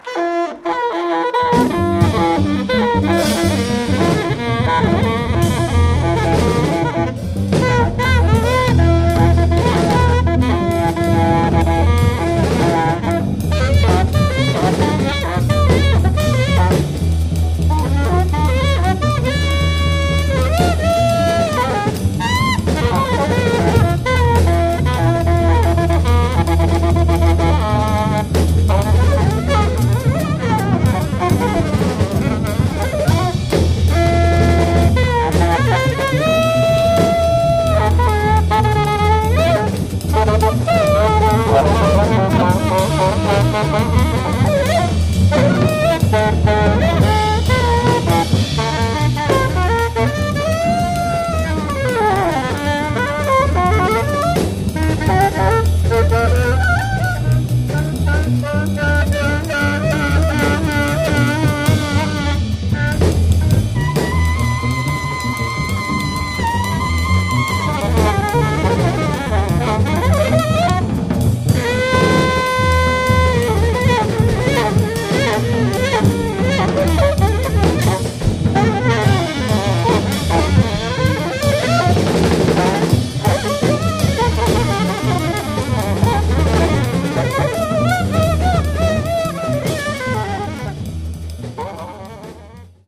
Recorded at Crescendo, Norrköping, Sweden